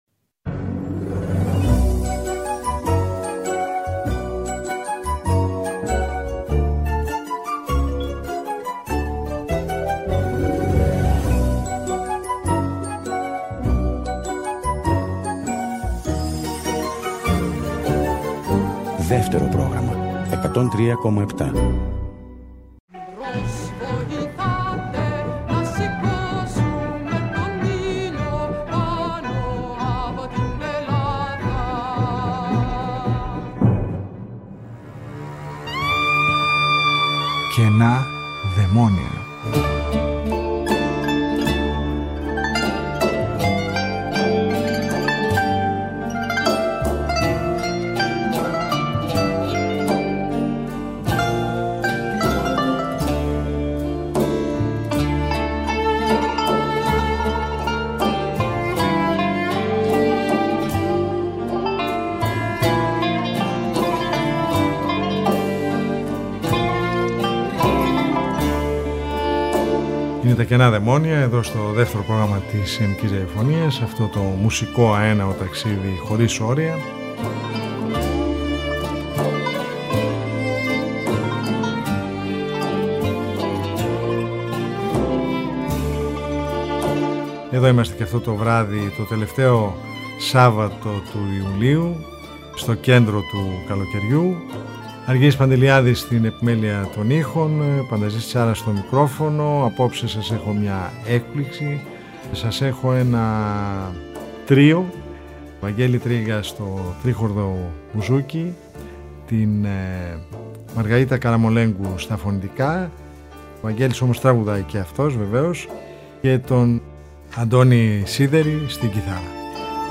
Μια συνομιλία από τα βάθη της ψυχής του σολίστα και μια ζωντανή παρουσίαση ενός μέρους από τις συνθέσεις του, ζωντανά από το στούντιο L2 της Ελληνικής Ραδιοφωνίας.
στην κιθάρα